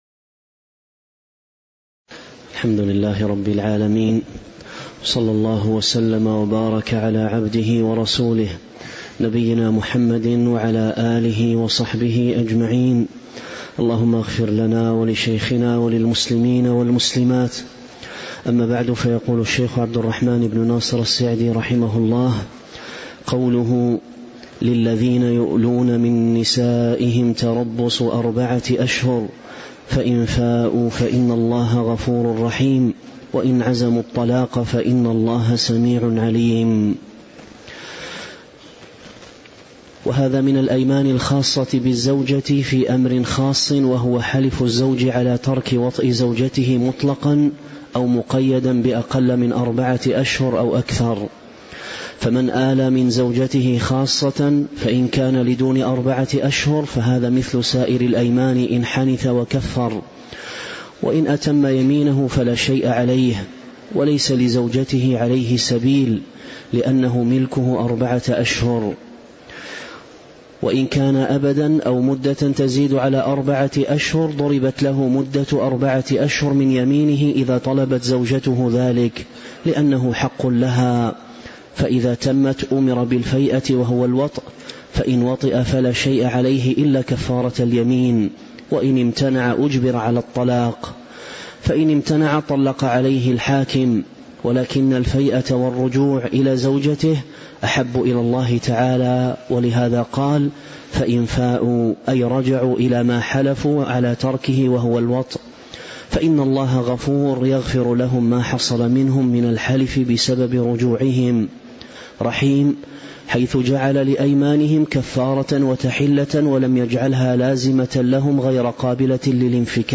تاريخ النشر ١٣ شعبان ١٤٤٦ هـ المكان: المسجد النبوي الشيخ